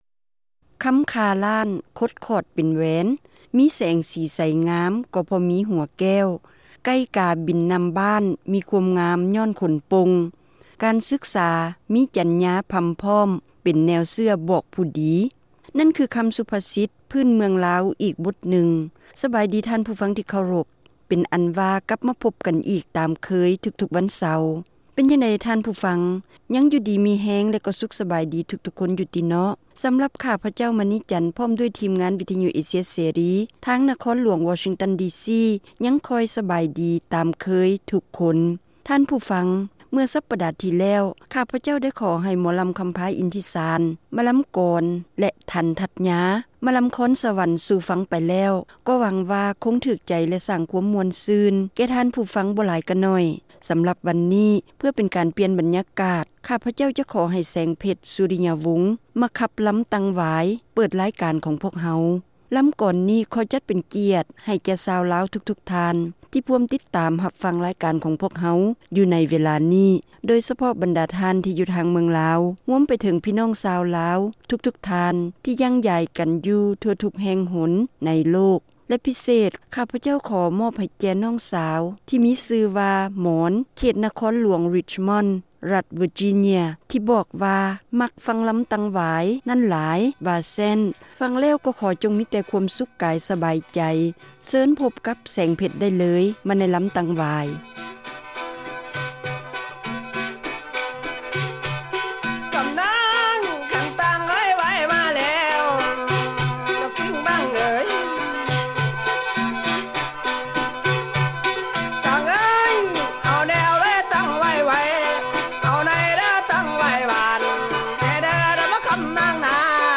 ຣາຍການໜໍລຳ ປະຈຳສັປະດາ ວັນທີ 2 ເດືອນ ກຸມພາ ປີ 2008